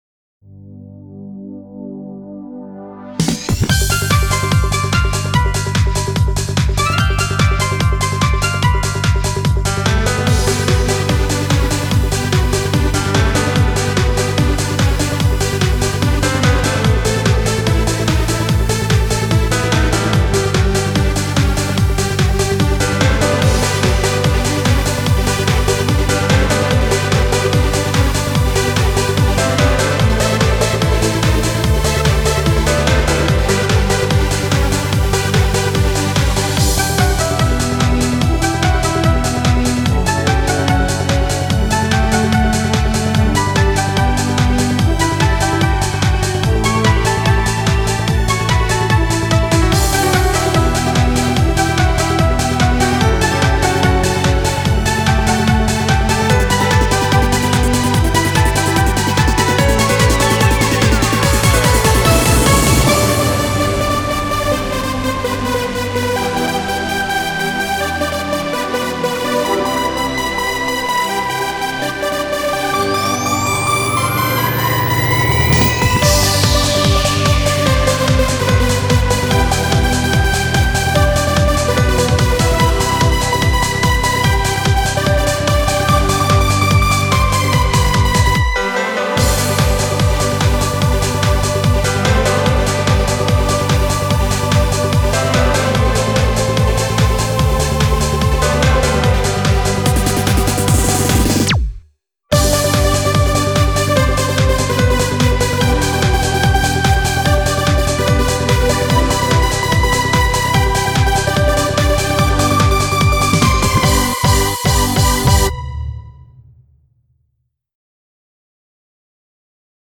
BPM146
Audio QualityPerfect (High Quality)
Genre: TRANCE.